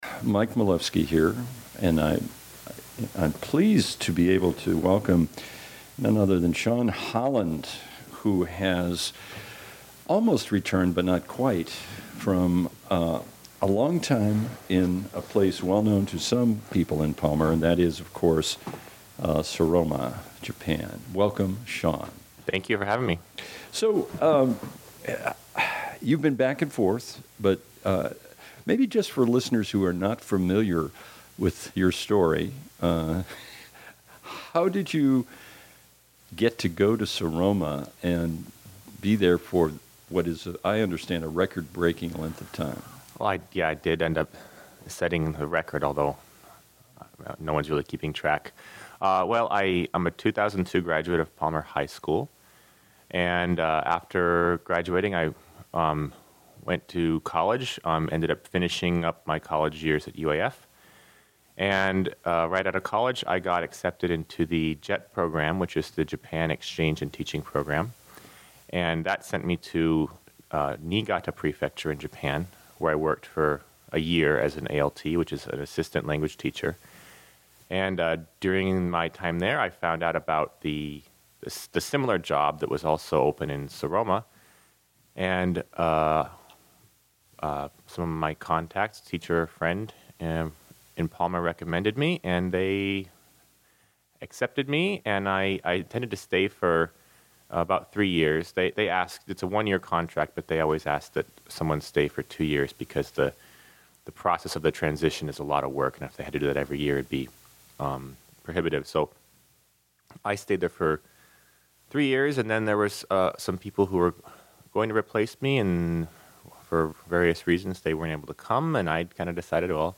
Interview: Palmer to Saroma and Back 1.23.15